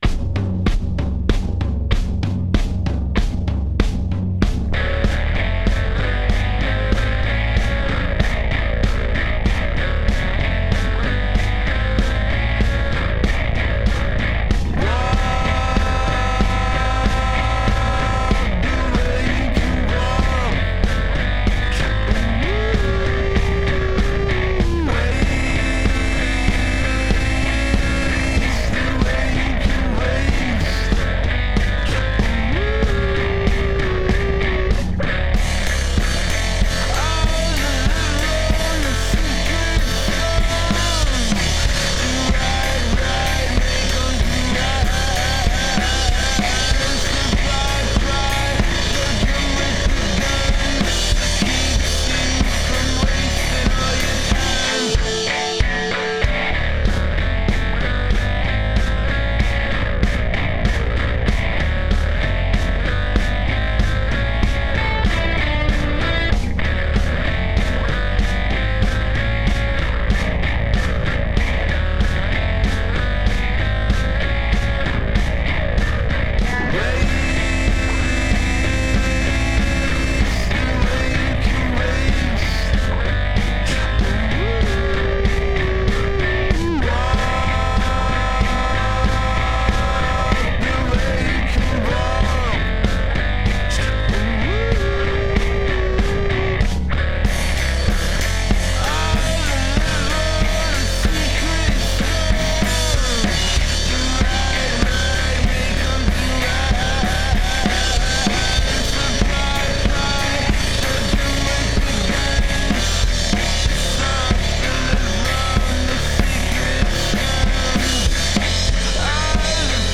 a duo from New Jersey